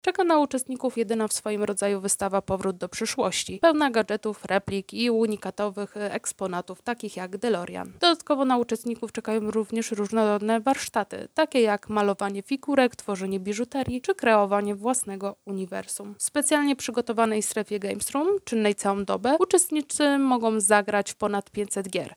O tym, co jeszcze będzie czekało na odwiedzających StarFest 2025, mówi